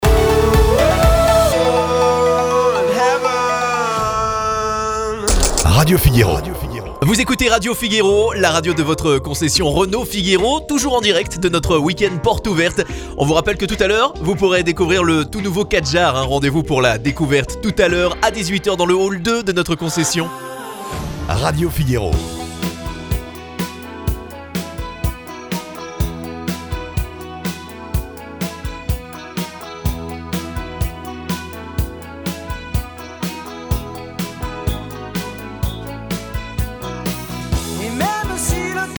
Une radio de concessionnaire.
Voici un exemple de radio Figuéro (Concession Renault), qui utilise un de nos animateurs pour mettre en valeur son week-end porte ouverte sur sa radio.